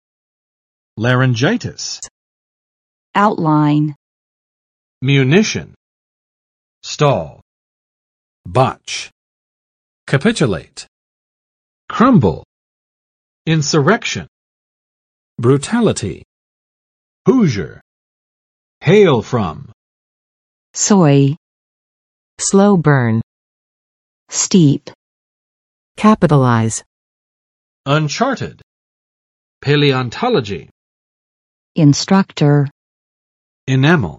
[͵lærɪnˋdʒaɪtɪs] n.【医】喉（头）炎
laryngitis.mp3